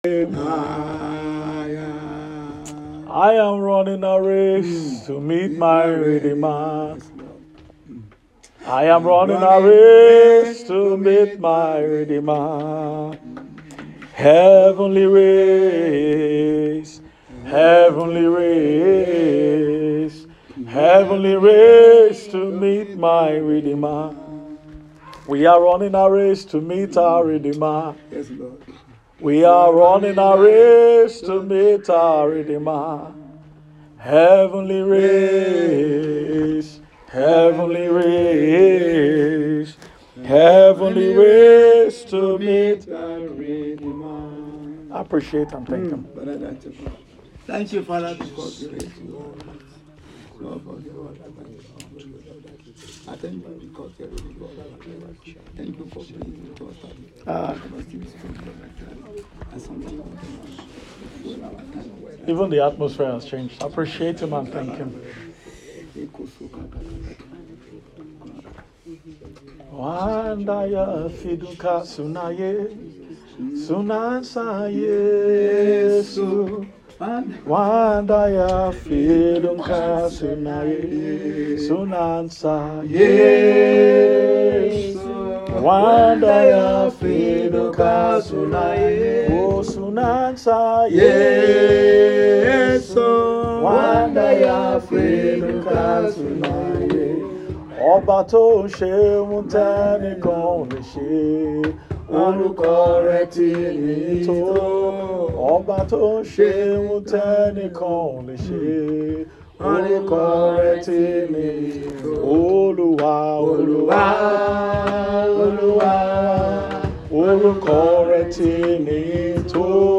Garam Outreach | Niger State [AUDIO] Tues 27th May 2025.